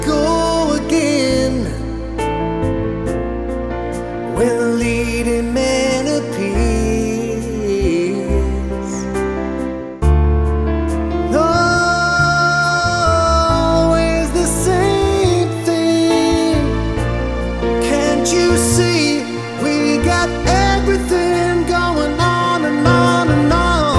Up 3 Female Key